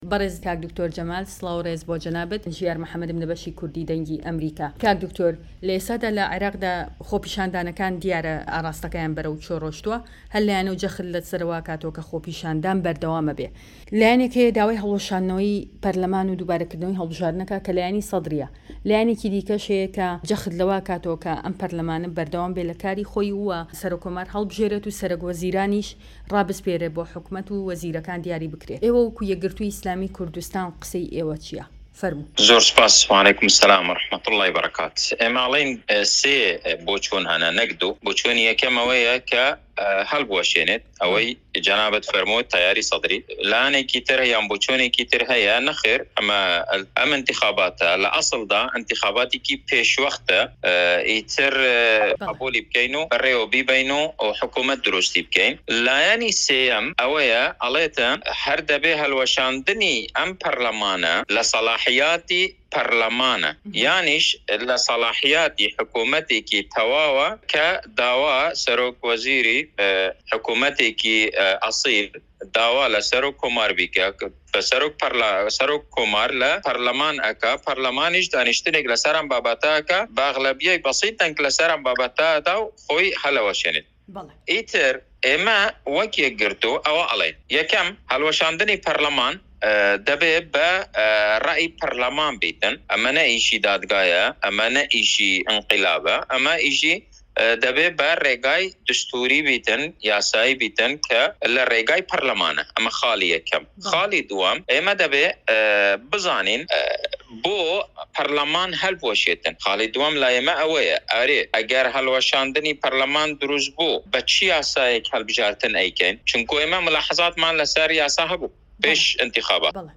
دەقی وتووێژ لەگەڵ د. جەمال کۆچەر